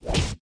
attack.opus